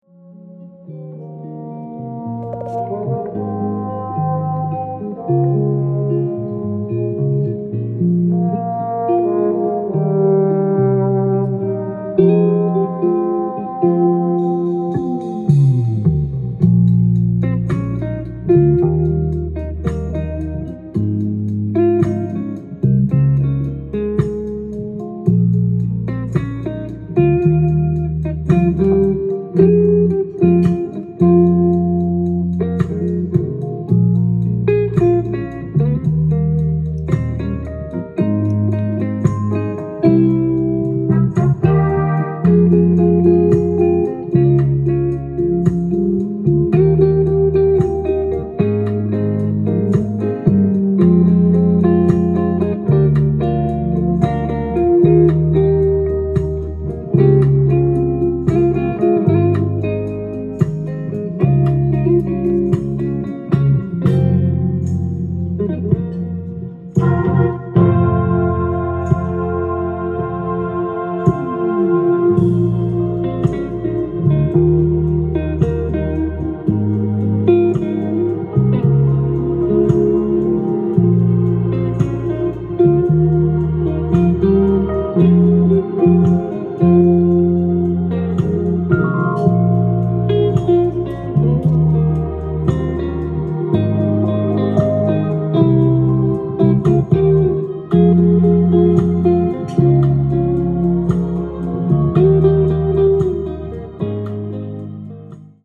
店頭で録音した音源の為、多少の外部音や音質の悪さはございますが、サンプルとしてご視聴ください。
Bass
Drums
Horn [Baritone]